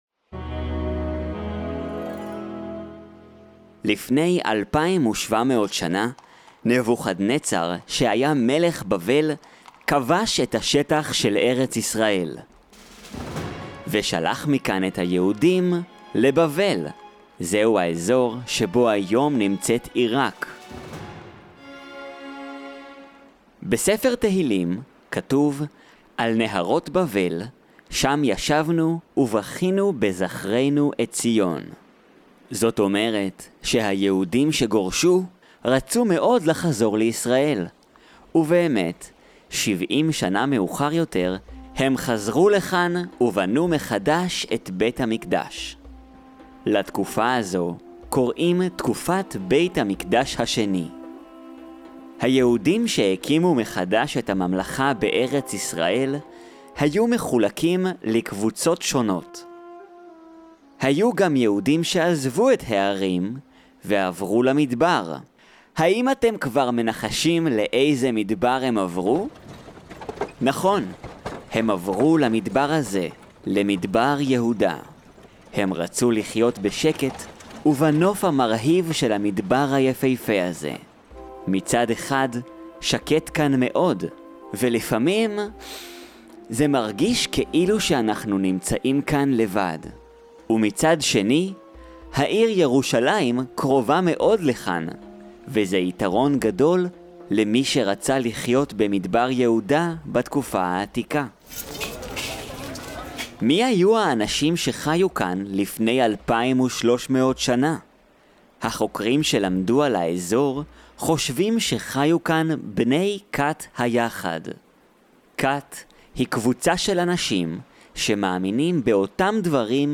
Qumran-Accessible-Part-2.mp3